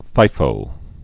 (fīfō)